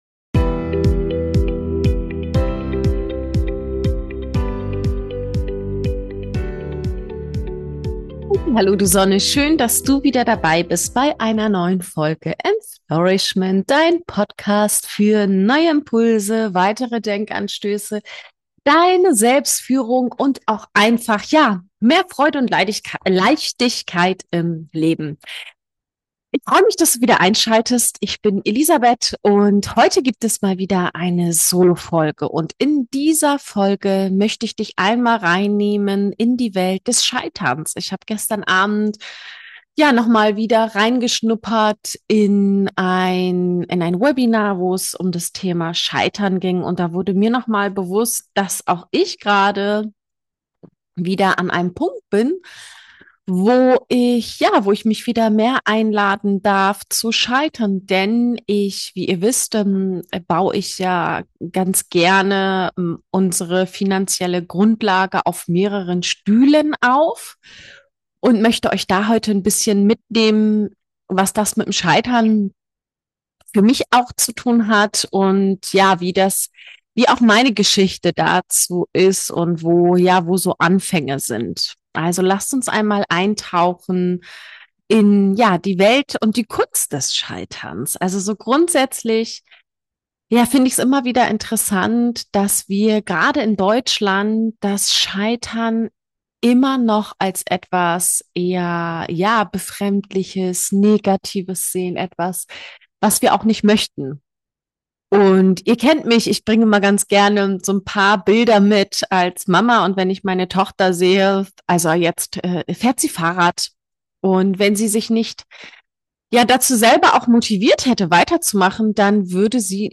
In dieser Solo-Folge nehme ich dich mit in meinen Alltag zwischen Abschied und Neuanfang, Zwischenbilanz und Selbstführung.